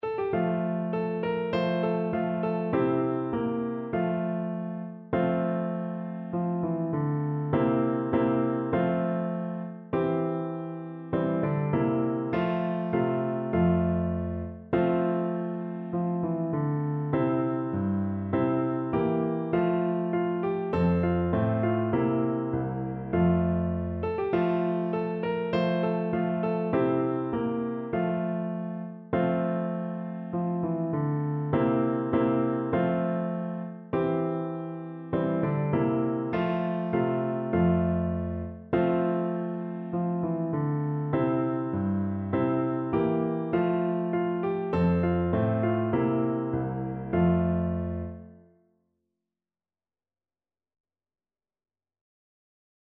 4/4 (View more 4/4 Music)
Moderato
Traditional (View more Traditional Voice Music)